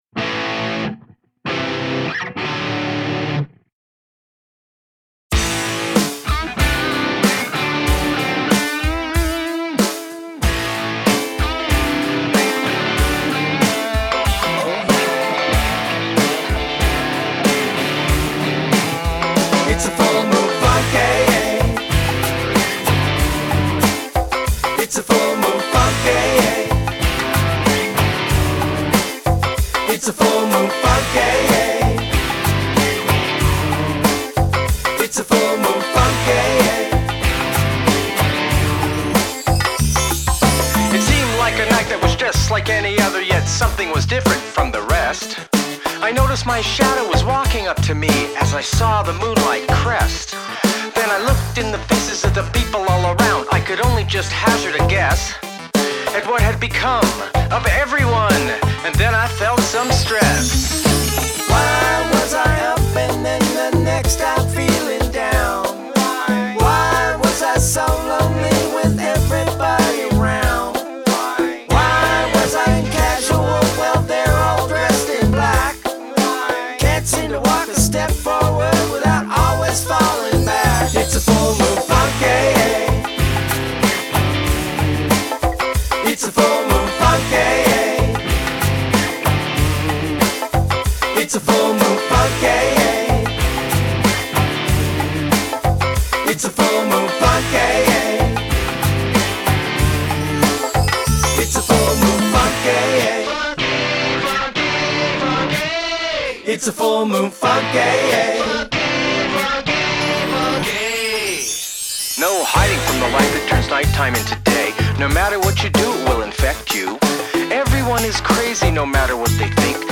Lead guitar
Moog synth